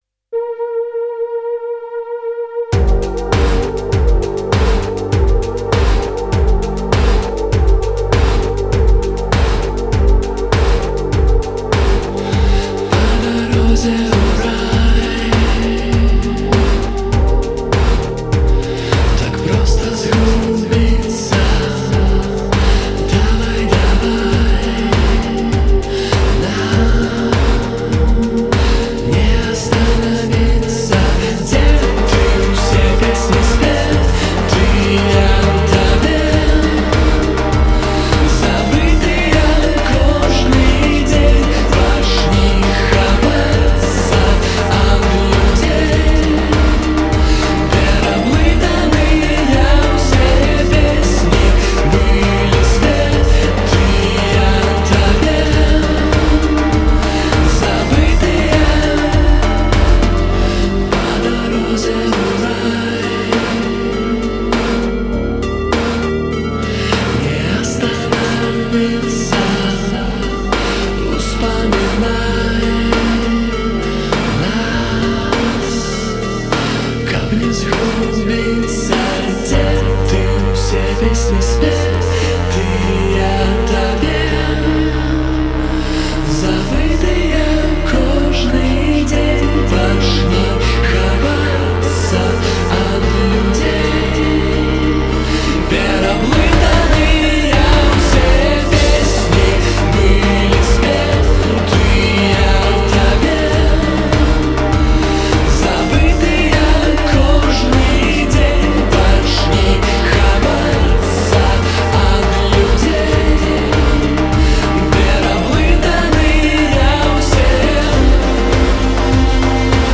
вакал, гітара
бас-гітара